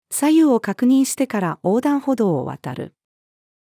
左右を確認してから横断歩道を渡る。-female.mp3